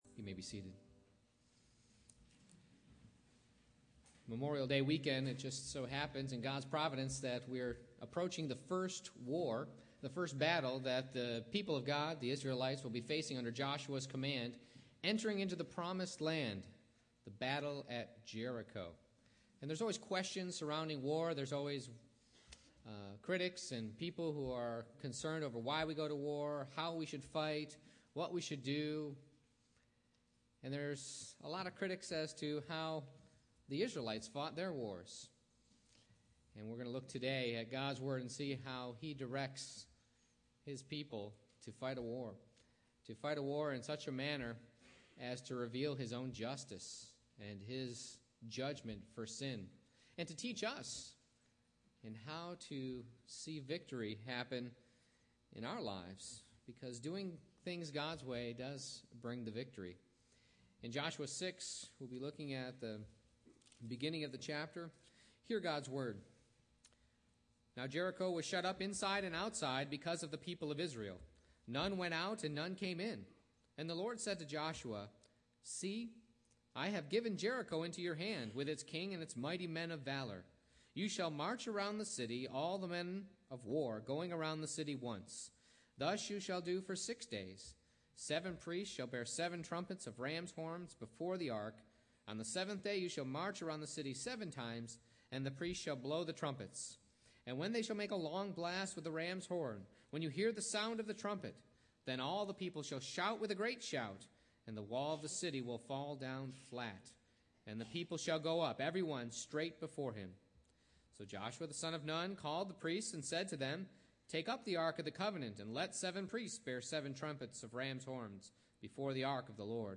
Joshua 6:20 Service Type: Morning Worship I. You want me to do what?